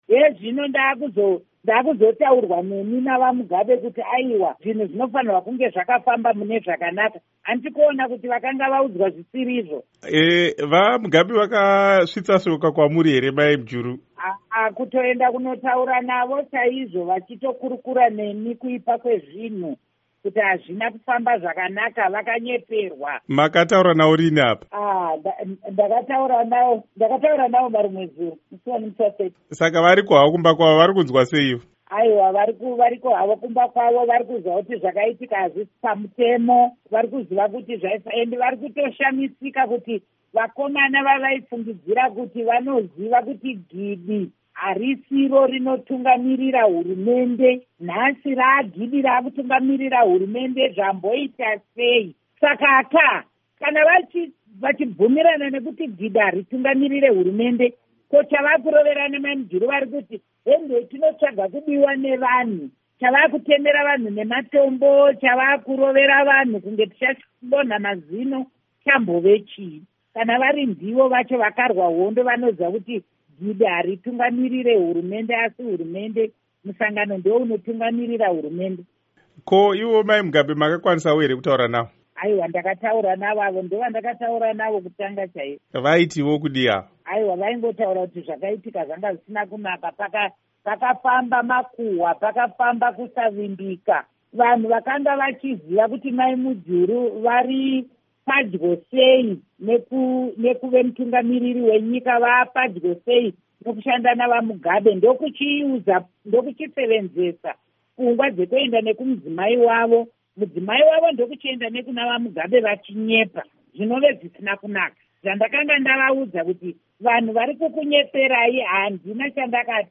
Hurukuro naAmai Joice Mujuru